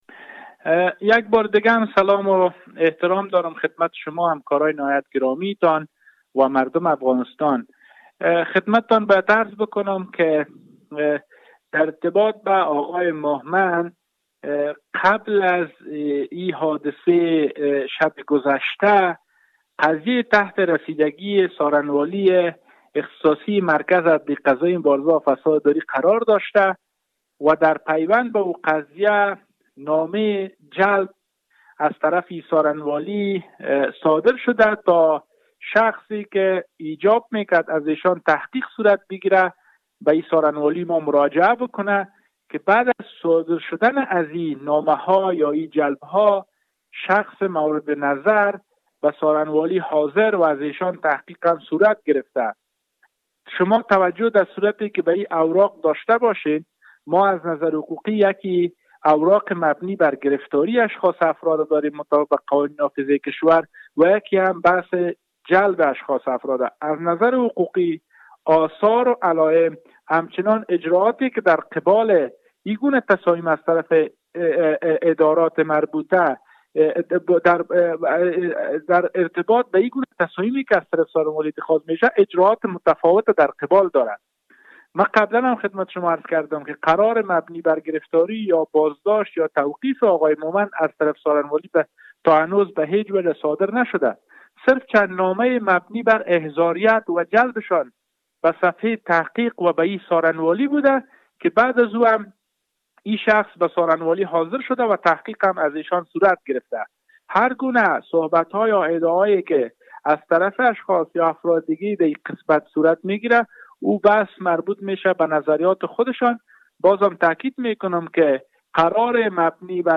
څرگندونې